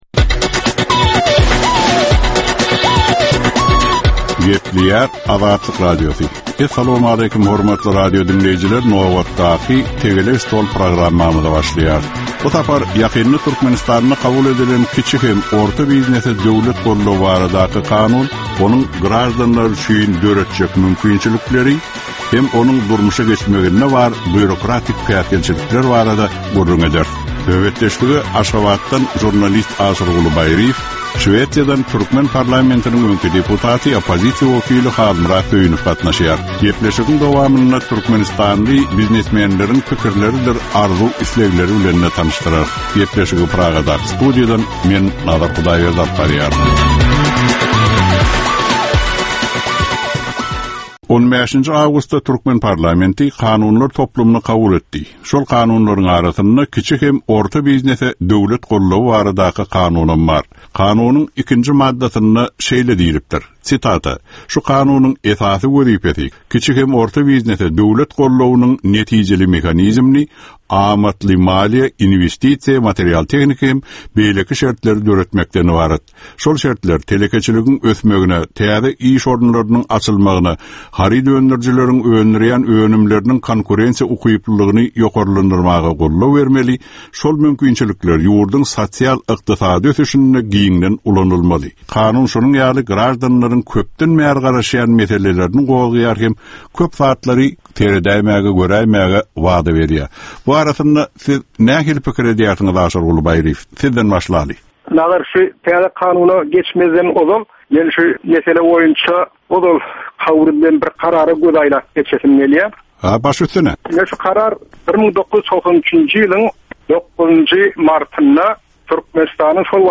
Jemgyýetçilik durmuşynda bolan ýa-da bolup duran soňky möhum wakalara ýa-da problemalara bagyşlanylyp taýyarlanylýan ýörite Tegelek stol diskussiýasy. 30 minutlyk bu gepleşikde syýasatçylar, analitikler we synçylar anyk meseleler boýunça öz garaýyşlaryny we tekliplerini orta atýarlar.